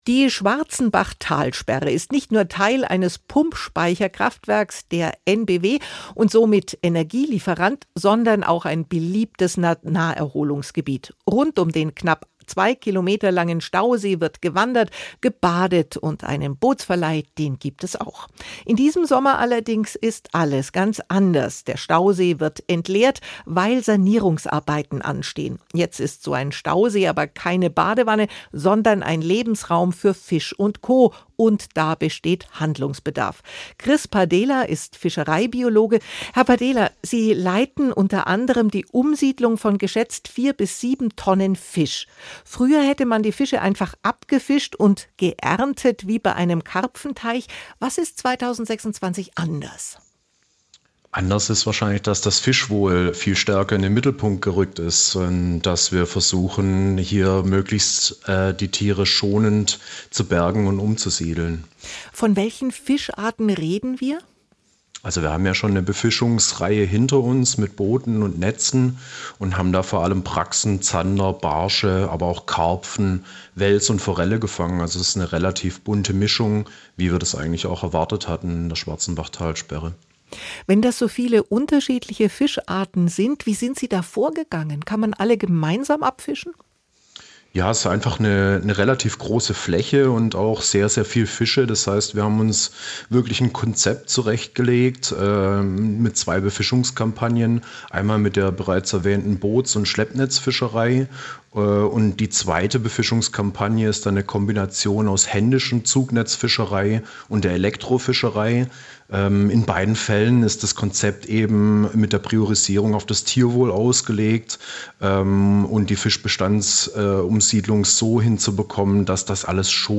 Interviews aus SWR Aktuell als Podcast: Im Gespräch